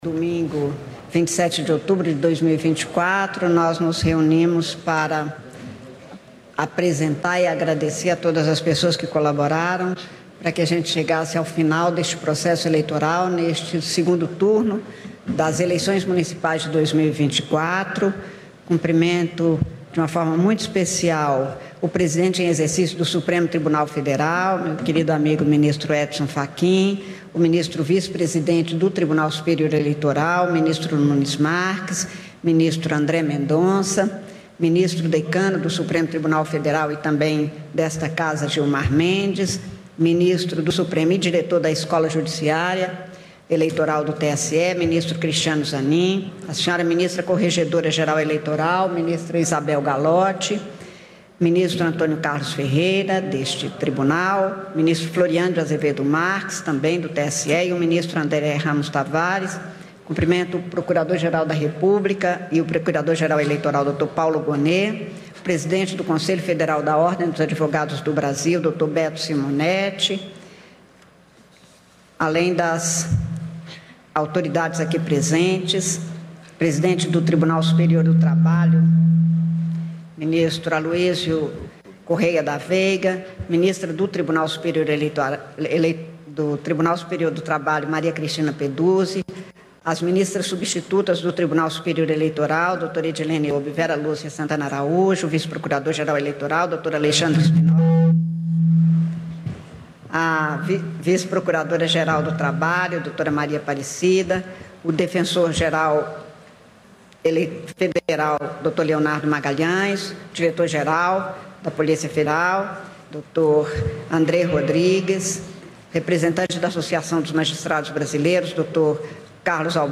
A ministra Cármen Lúcia, presidente do Tribunal Superior Eleitoral, afirmou em entrevista coletiva na noite deste domingo (27) que o segundo turno aconteceu da forma como devem ser todas as eleições: tranquilas e com respeito aos eleitores. Segundo Cármen Lúcia, essa eleição é a demonstração de que um clima de violência, intolerância e tentativa de fraudar dados para compelir eleitores é algo fora do comum. Confira a íntegra da fala da presidente do TSE.